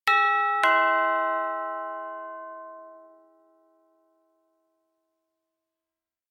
Doorbell